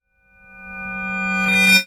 time_warp_reverse_spell_04.wav